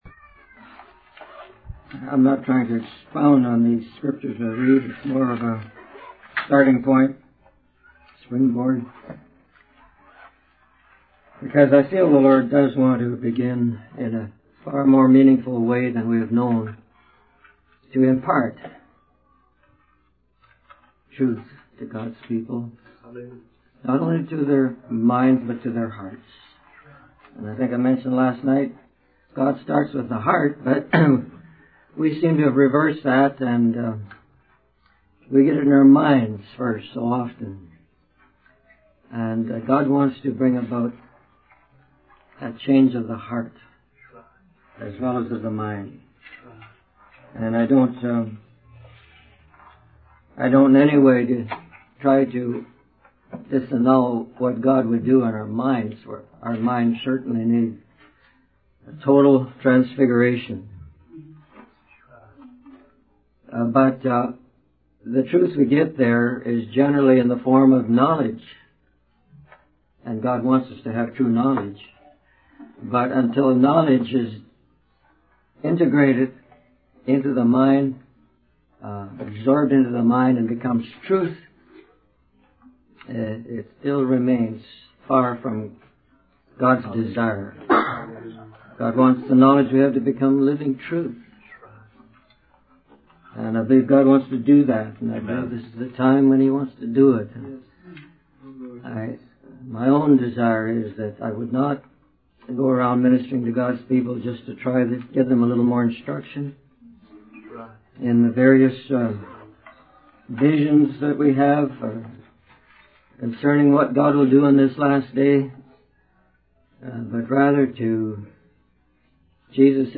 In this sermon, the speaker emphasizes the importance of seeking the truth from God rather than relying on various cults or books. He uses an analogy of a bank teller learning to identify counterfeit bills to illustrate the need for discernment in spiritual matters. The speaker then discusses the power of the law of the spirit of life in Christ Jesus, which surpasses the law of sin and death.